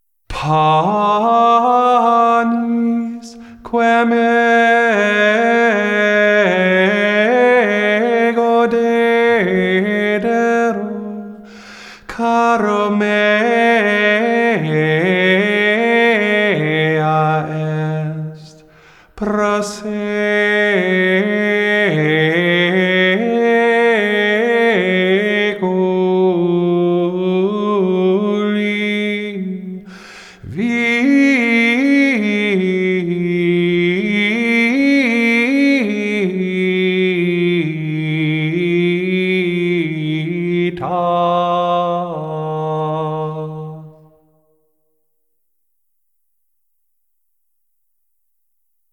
4442 COMMUNION ANTIPHON 15th Sunday after Pentecost